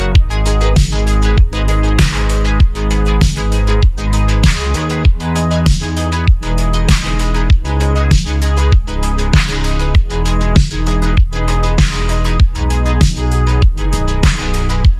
audio-to-audio music-continuation music-generation